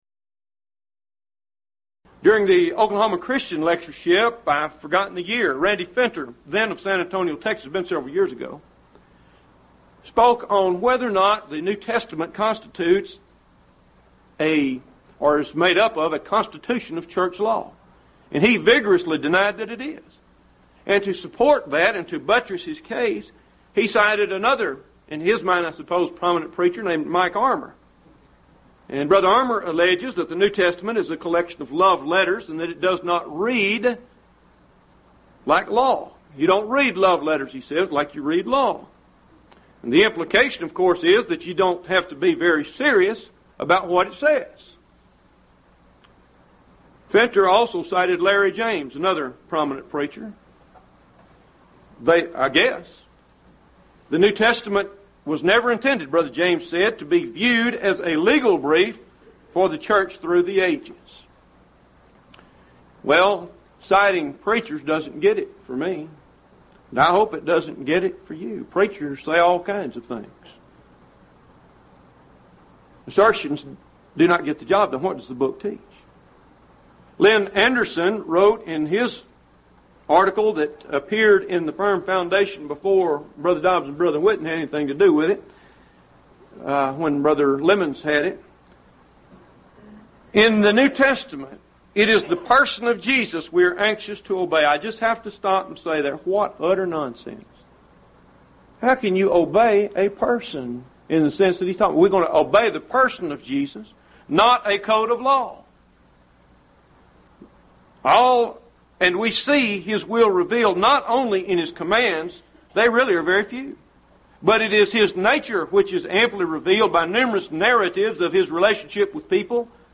Event: 1st Annual Lubbock Lectures
lecture